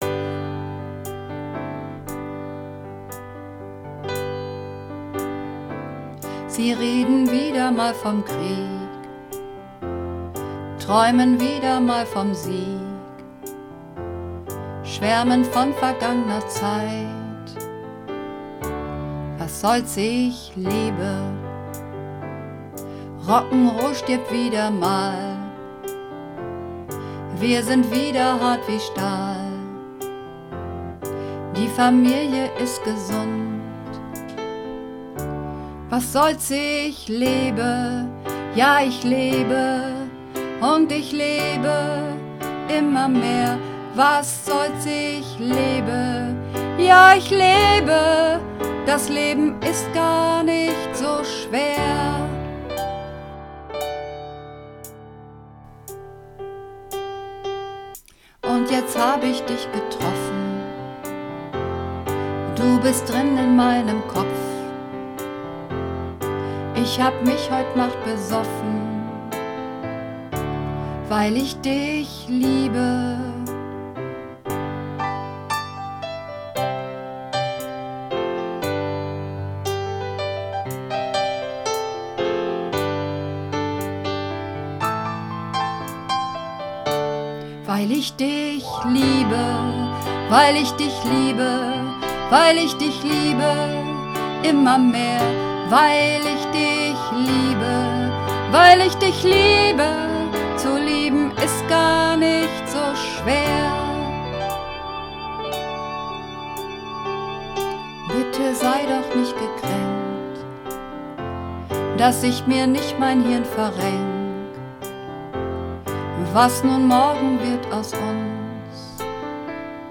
Übungsaufnahmen - Lass uns leben
Lass uns leben (Alt)
Lass_uns_leben__1_Alt.mp3